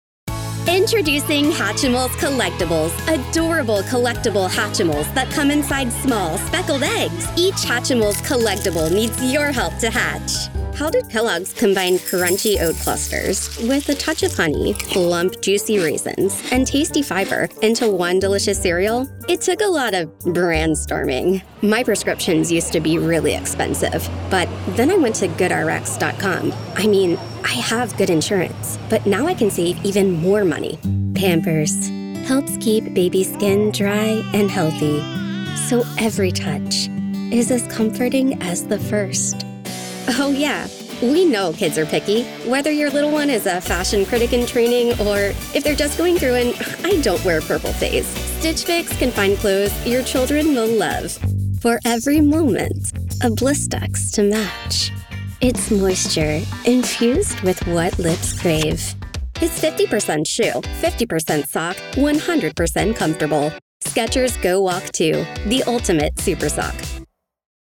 Female
She brings charm, clarity, and versatility to every read—from conversational and friendly to quirky, energetic, or sincere.
Radio Commercials
Natural & Engaging Ads
Words that describe my voice are Youthful, Conversational, Expressive.